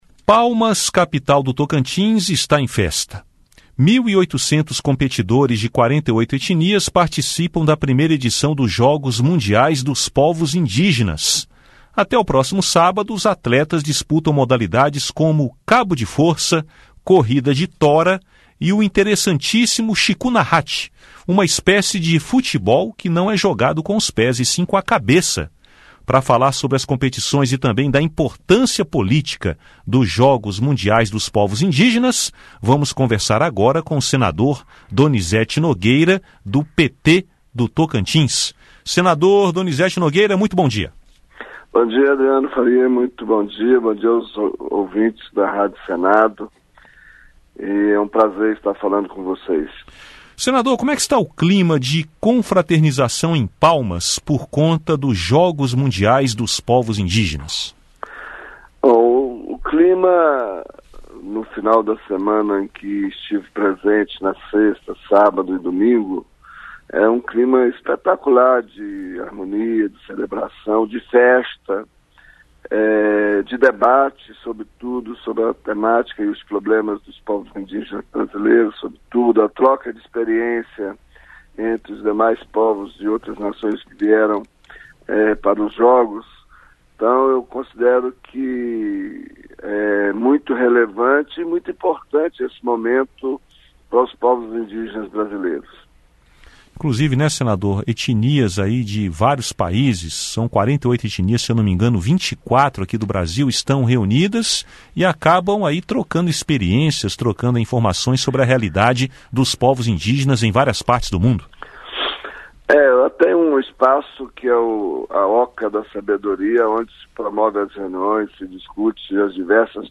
Entrevista com o senador Donizeti Nogueira (PT-TO).